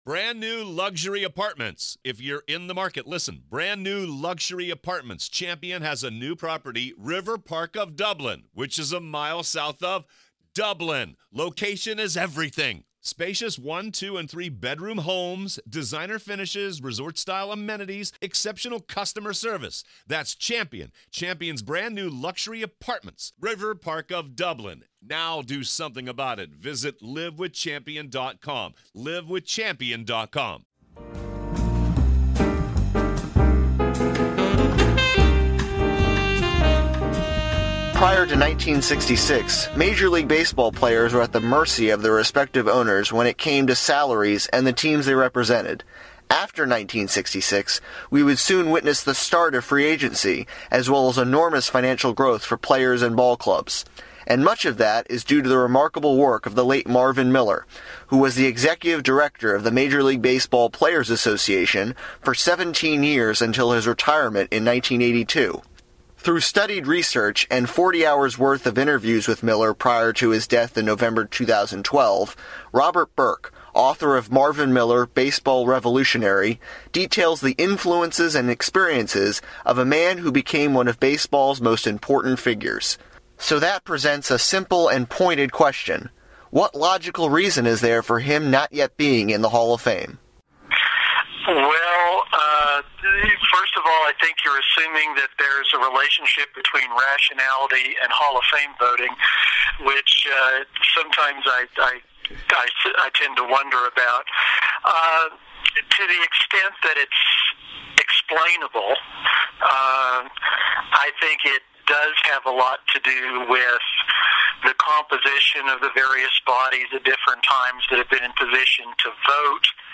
Audio clips courtesy of ‘Baseball: Inning 8’ by Ken Burns